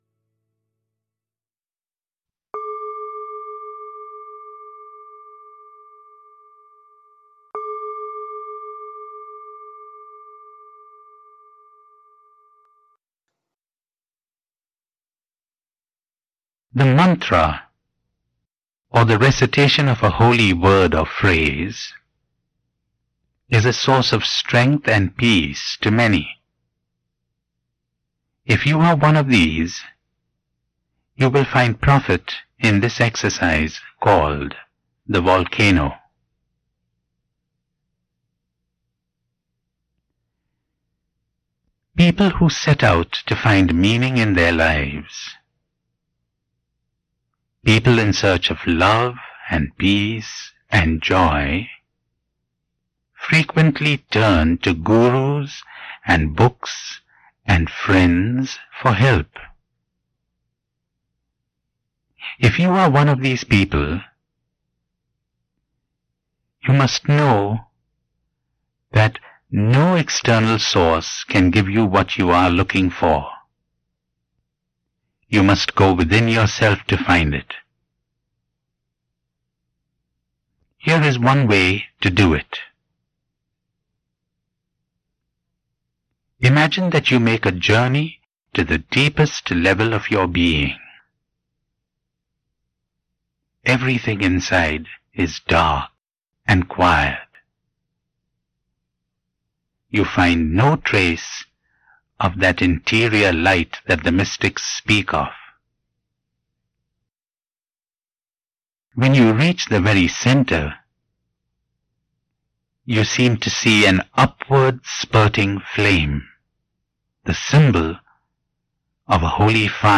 Audio Program for Guided Meditations for Springtime | Audio Clip from Anthony DeMello | DeMello Spirituality Center
Each reflection is followed by a period of silence completed by a meaningful story.